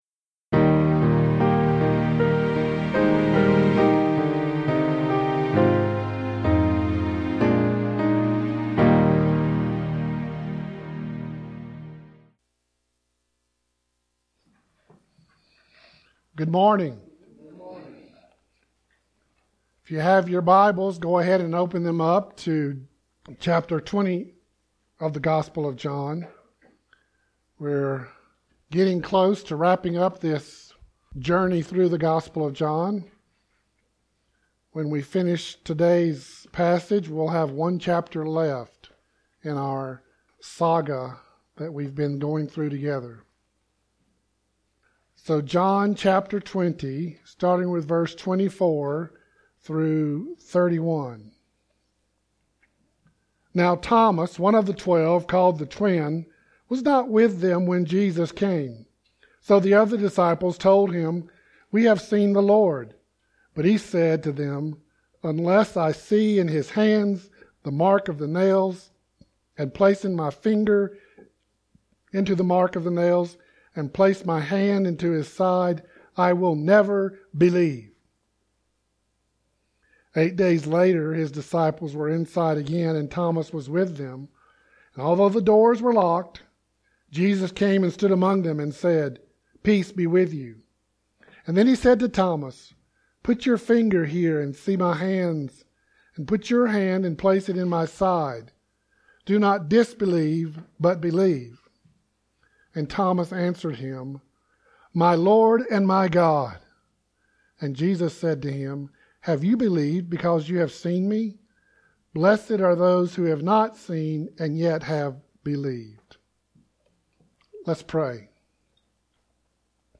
The latest sermon & selected archives from Castle Rock Baptist Church, Castle Rock, Colorado.